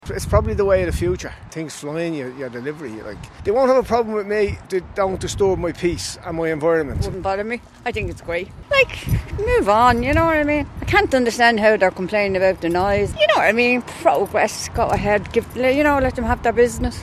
These people in Tallaght are happy enough to have the likes of takeaways and coffees delivered by drones.